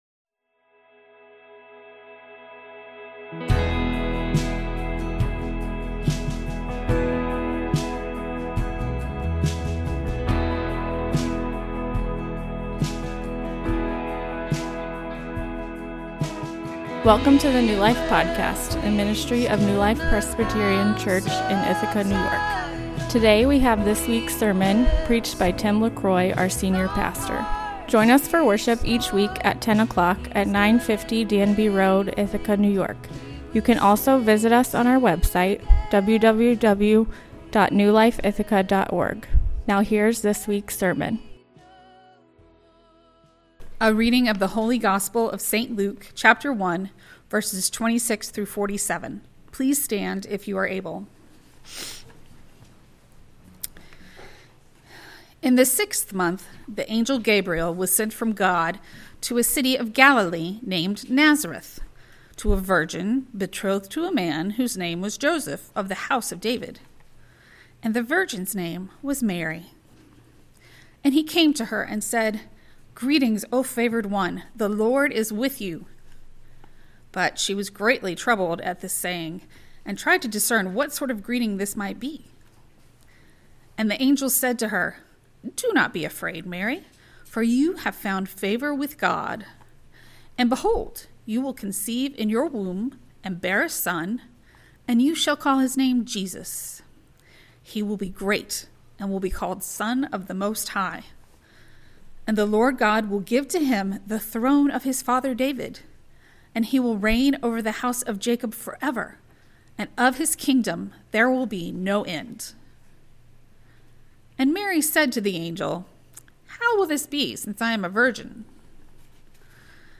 Sermon Outline: 1.